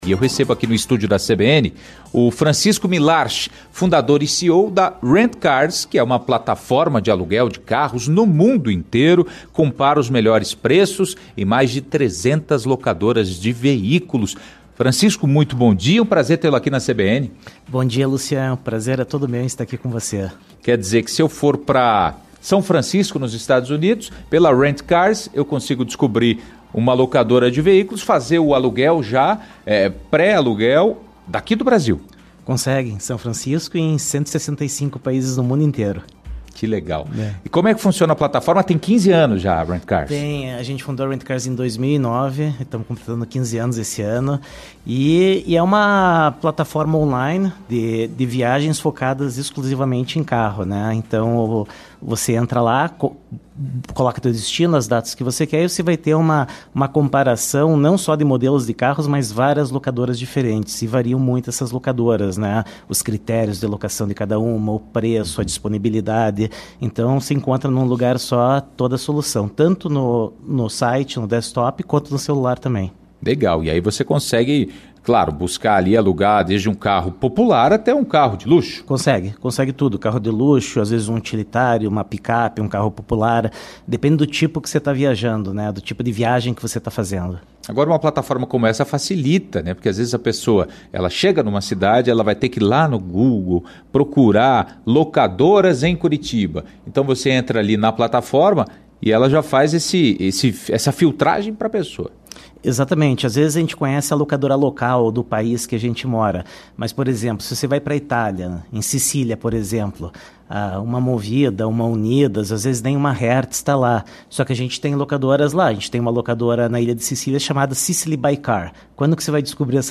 Com a chegada do fim de ano e das férias, aumenta a procura por locações de automóveis. Para falar sobre esse assunto, nós conversamos, no CBN Curitiba 1ª Edição de hoje (19)